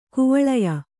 ♪ kuvaḷaya